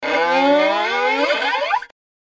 Cartoon Violin Sound